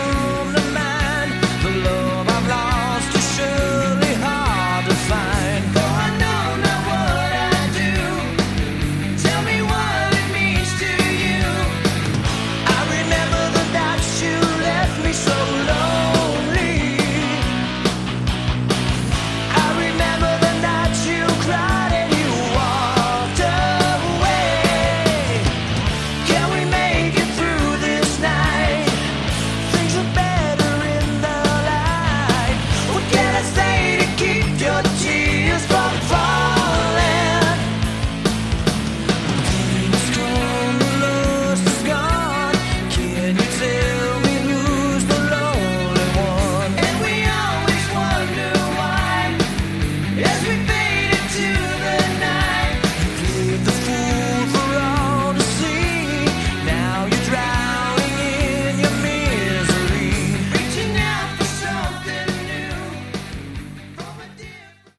Category: Melodic Metal
guitar, keyboards and vocals